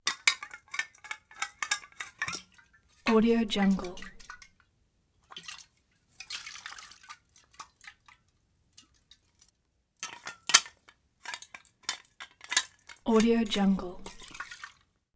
دانلود افکت صوتی هم زدن نوشیدنی در قوطی فلزی
Foley Cannister Canteen Unscrew Drink Moves Recap Rattle royalty free audio track is a great option for any project that requires human sounds and other aspects such as a canteen, unscrew and drink.
Sample rate 16-Bit Stereo, 44.1 kHz
Looped No